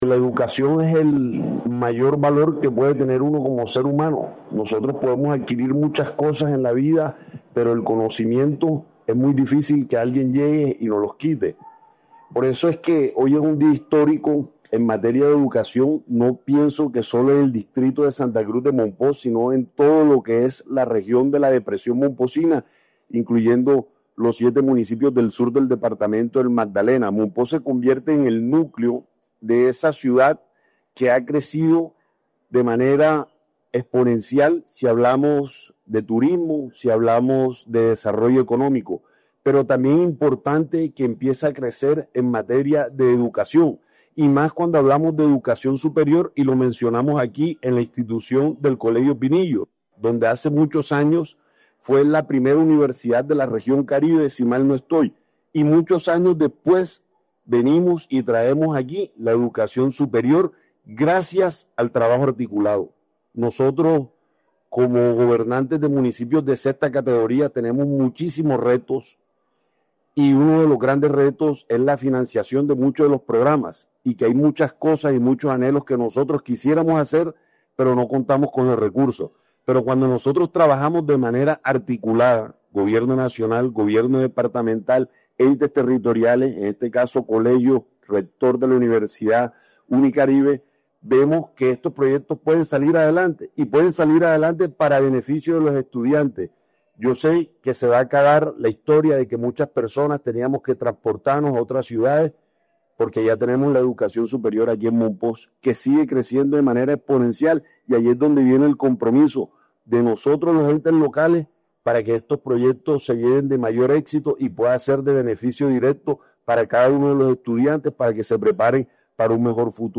JUAN-NICOLAS-SINNING-ALCALDE-MOMPOX.mp3